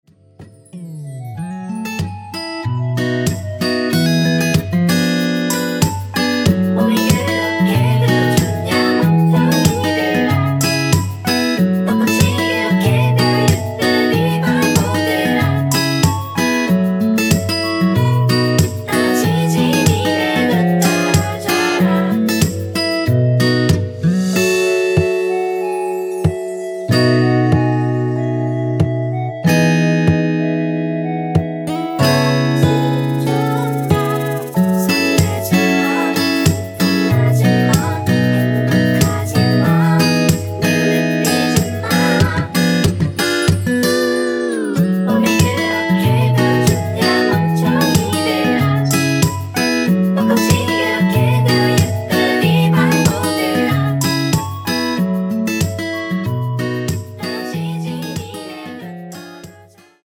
원키에서(+3)올린 멜로디와 코러스 포함된 MR 입니다.(미리듣기 확인)
◈ 곡명 옆 (-1)은 반음 내림, (+1)은 반음 올림 입니다.
앞부분30초, 뒷부분30초씩 편집해서 올려 드리고 있습니다.
중간에 음이 끈어지고 다시 나오는 이유는